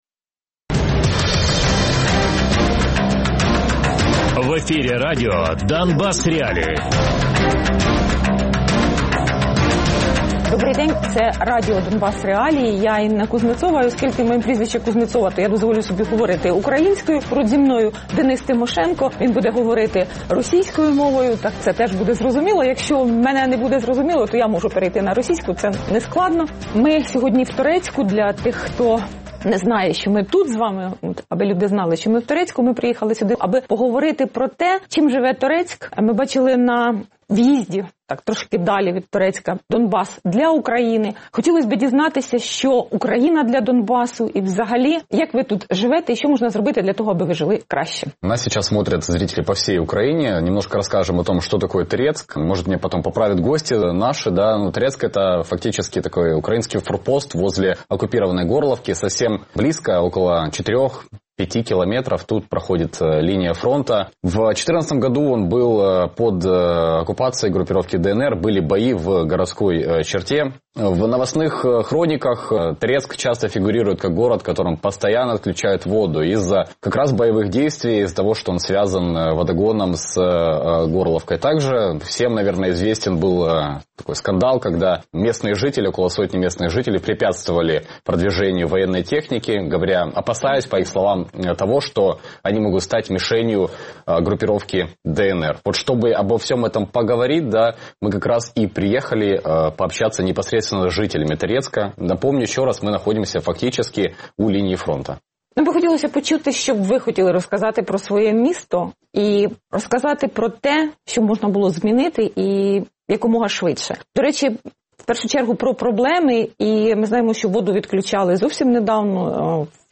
Відкрита студія із жителями Торецька.